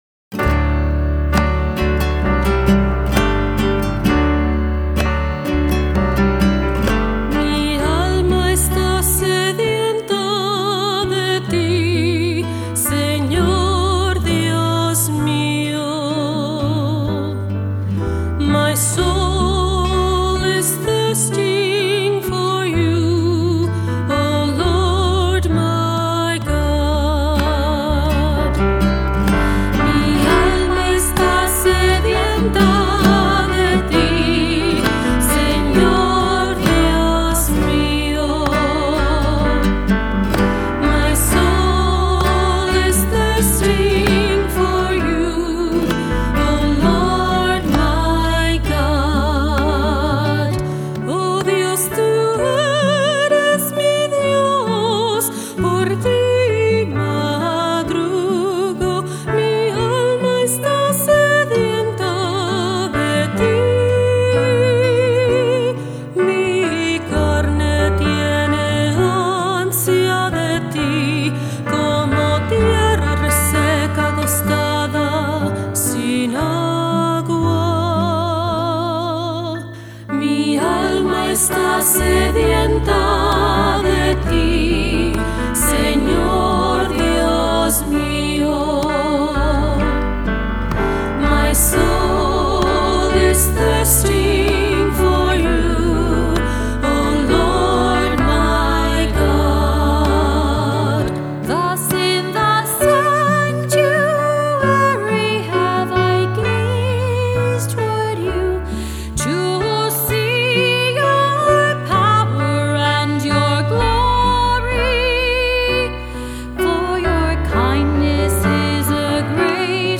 Voicing: Three-part mixed; Cantor; Assembly